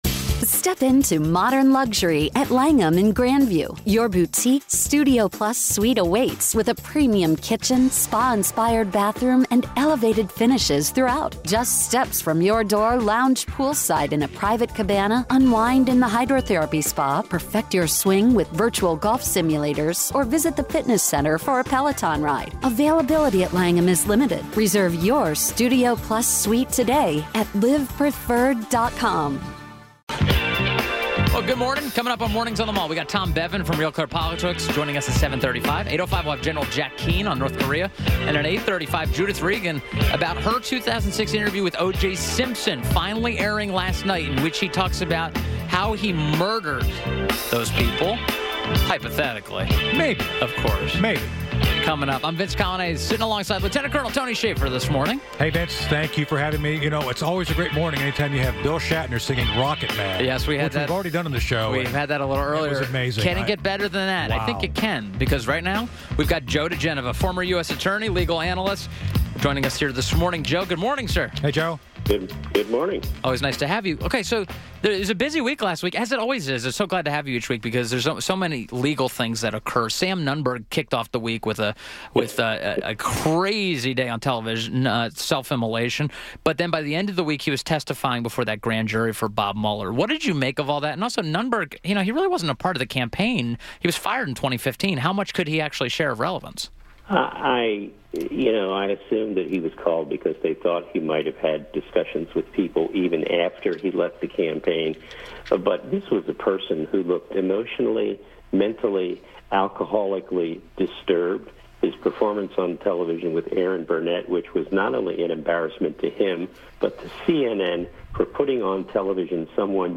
WMAL Interview - JOE DIGENOVA - 03.12.18